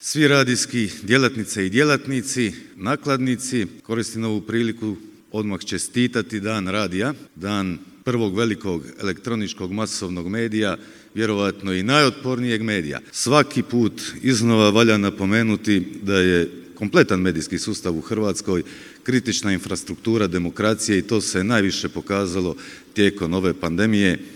Tim povodom u petak je tradicionalno održan 5. Hrvatski radijski forum, na kojem su vodeći ljudi iz kulturne i kreativne industrije govorili upravo o Radiju i kreativnoj ekonomiji.
Potom je predsjednik Vijeća za elektroničke medije Josip Popovac uputio čestitku: